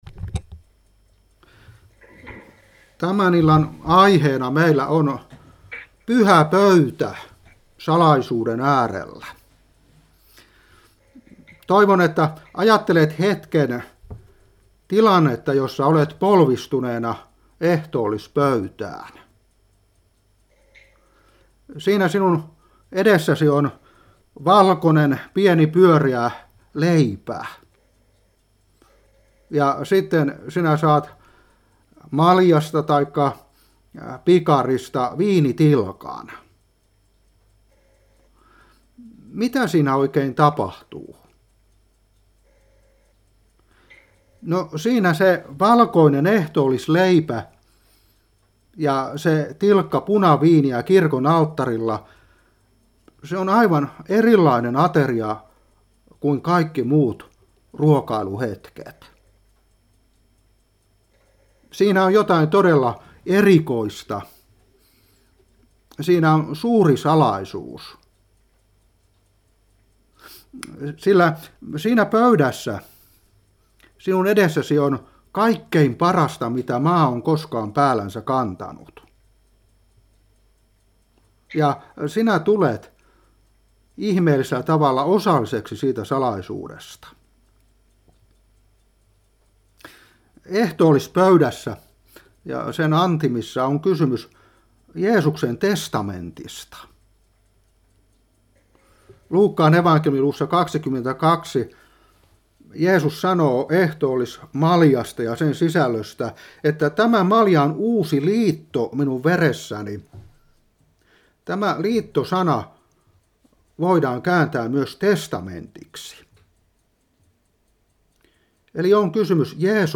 Opetuspuhe 2021-5.